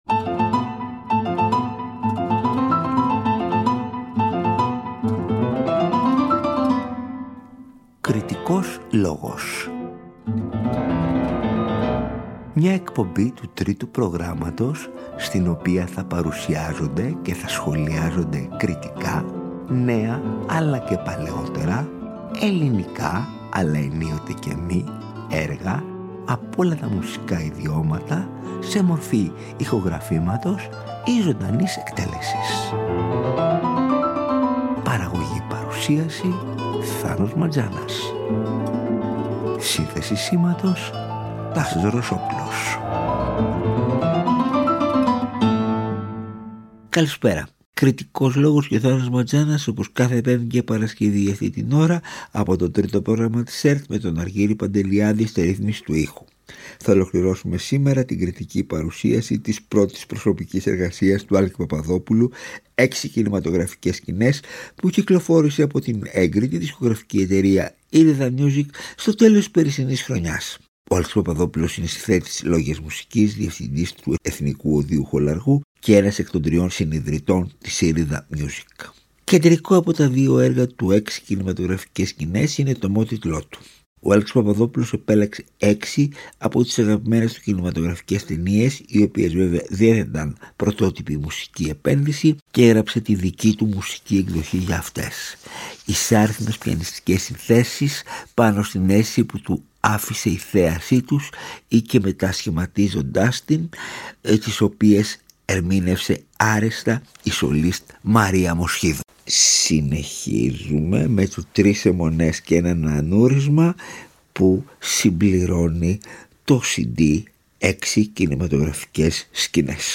παρουσιάζει και αναλύει ο κριτικός μουσικής